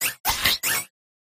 applin_ambient.ogg